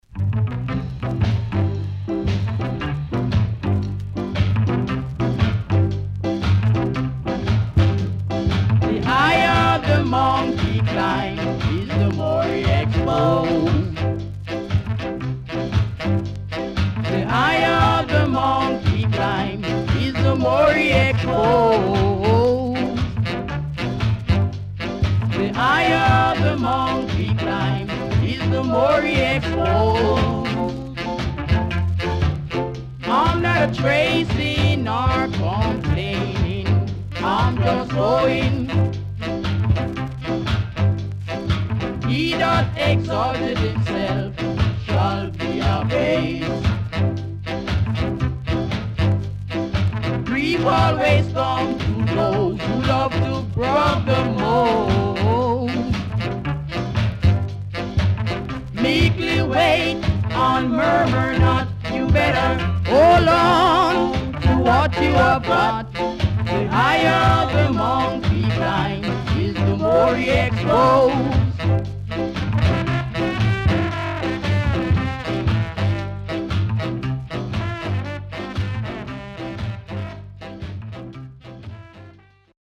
SKA
W-Side Good Ska Vocal
SIDE A:薄くジリジリしたノイズあり、少しプチノイズ入ります。